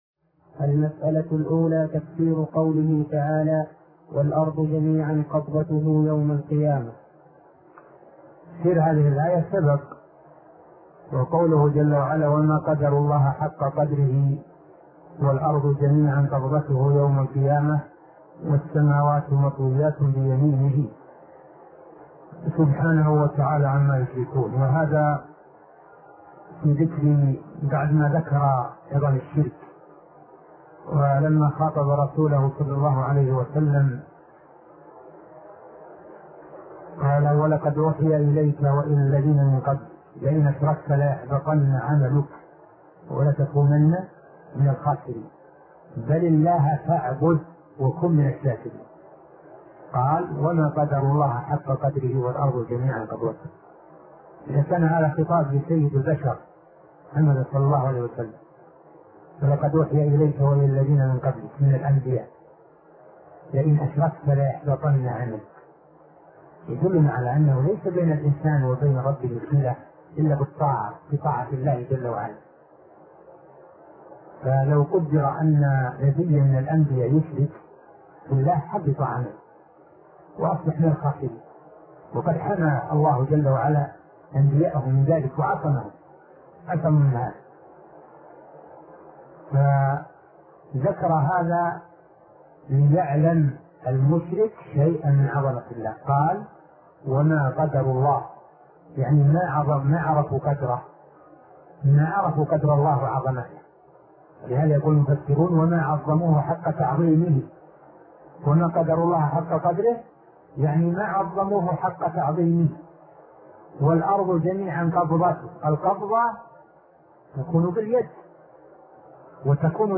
عنوان المادة الدرس ( 139) شرح فتح المجيد شرح كتاب التوحيد تاريخ التحميل الجمعة 16 ديسمبر 2022 مـ حجم المادة 24.60 ميجا بايت عدد الزيارات 210 زيارة عدد مرات الحفظ 131 مرة إستماع المادة حفظ المادة اضف تعليقك أرسل لصديق